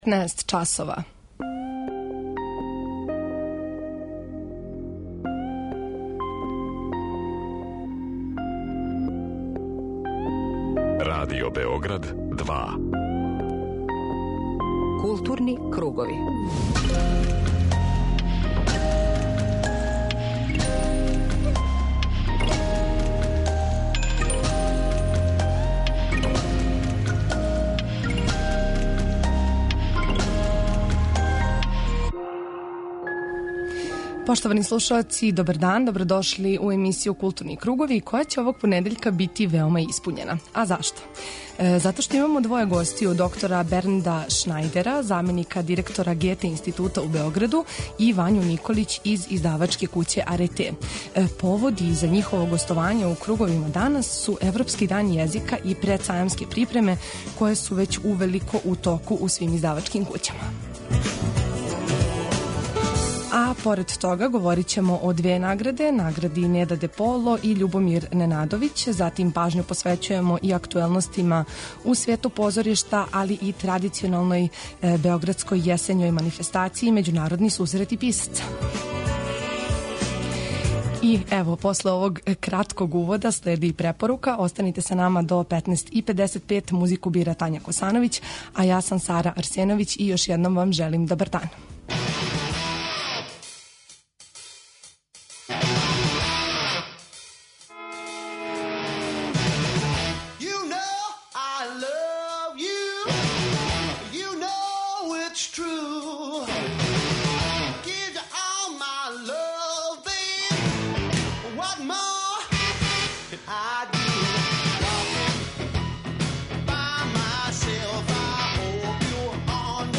Дневни магазин културе Радио Београда 2
У емисији Културни кругови данас имамо двоје гостију.